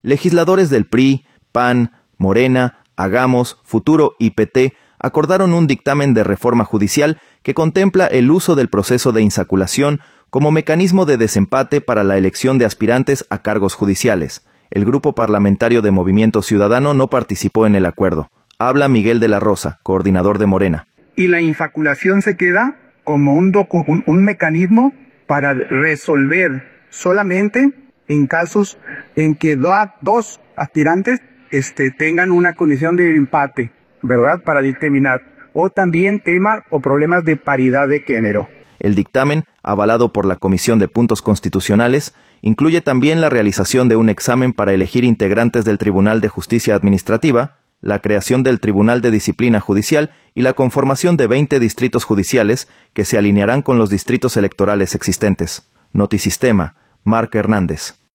Legisladores del PRI, PAN, Morena, Hagamos, Futuro y PT acordaron un dictamen de reforma judicial que contempla el uso del proceso de insaculación como mecanismo de desempate para la elección de aspirantes a cargos judiciales. El grupo parlamentario de Movimiento Ciudadano no participó en el acuerdo. Habla Miguel de la Rosa, coordinador de Morena.